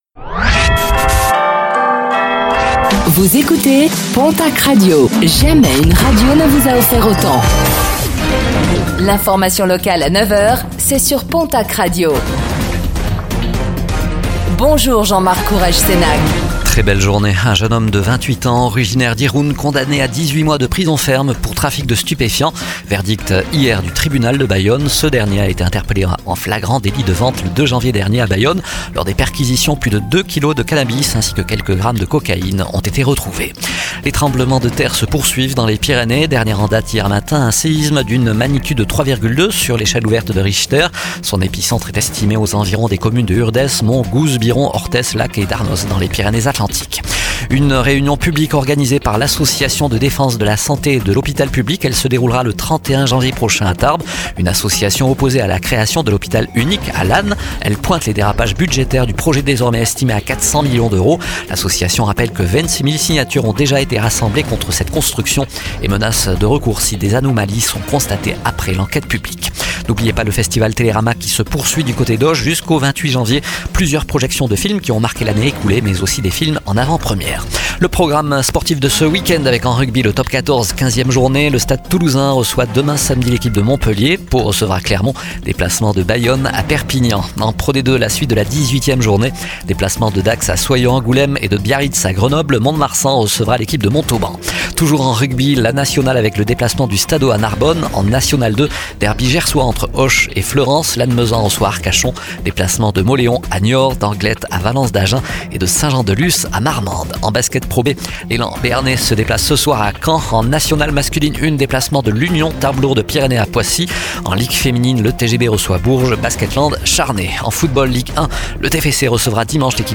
Réécoutez le flash d'information locale de ce vendredi 24 janvier 2025, présenté par